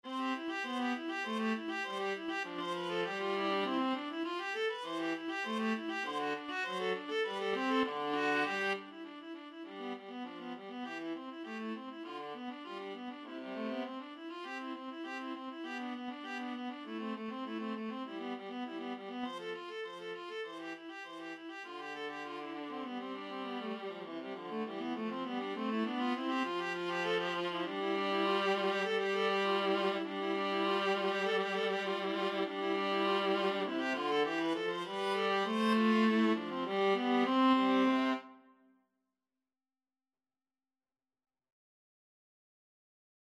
Free Sheet music for Viola Duet
Viola 1Viola 2
C major (Sounding Pitch) (View more C major Music for Viola Duet )
Allegro =200 (View more music marked Allegro)
Classical (View more Classical Viola Duet Music)